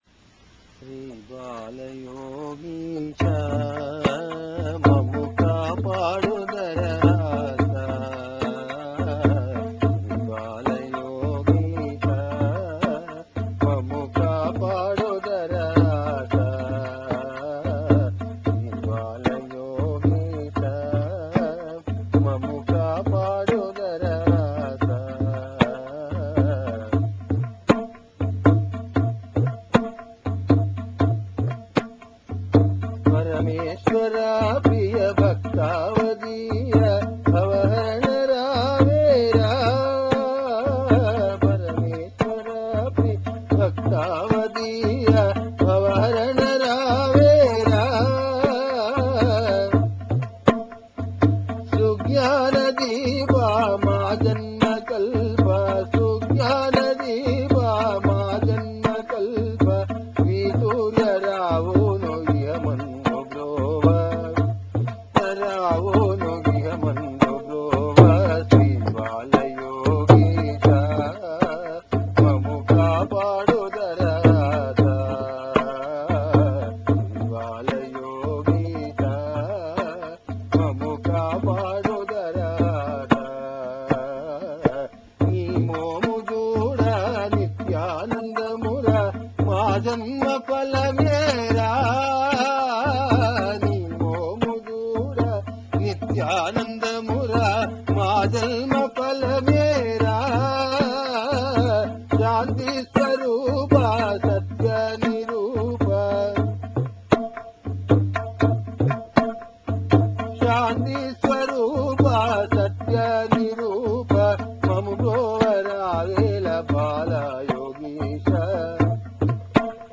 Recorded at the Bangalore ashram in 1993.